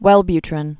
(wĕlby-trĭn)